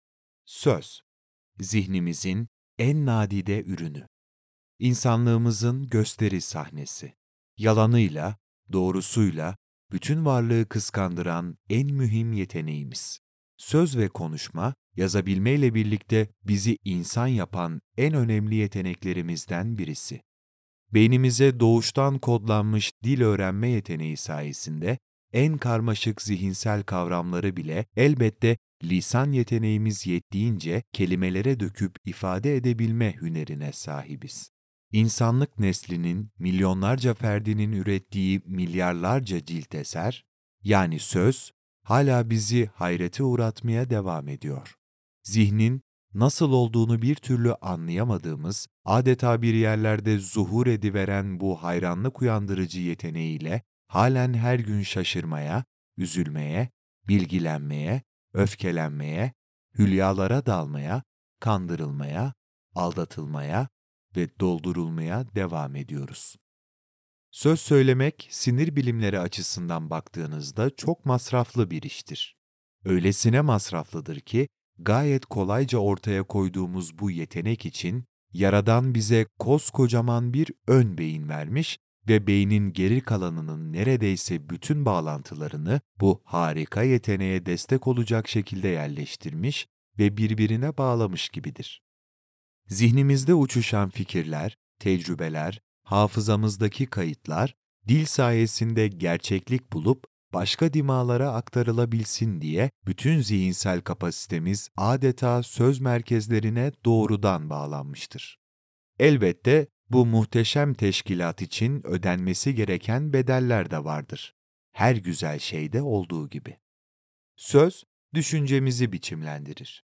*** Dikkat: Bu yazının seslendirilmiş versiyonunu aşağıdaki oynatıcı yoluyla dinleyebilirsiniz